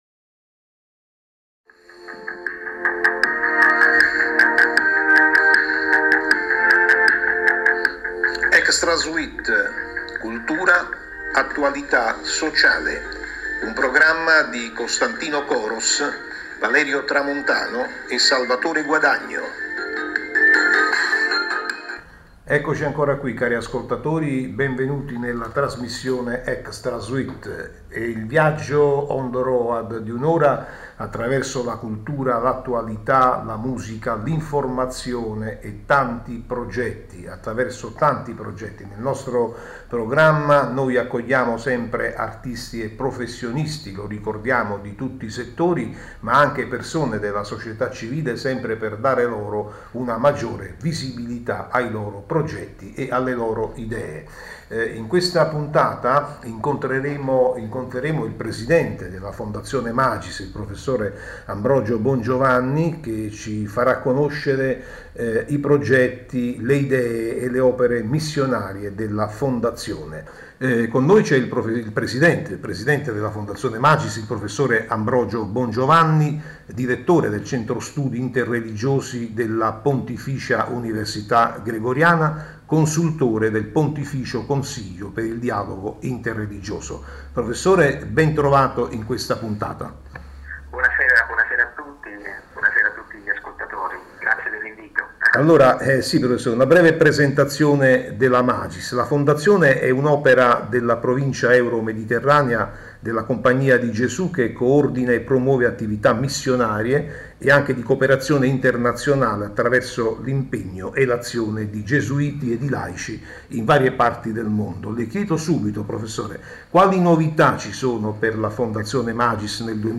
L’audio dell’intervista a Radio40web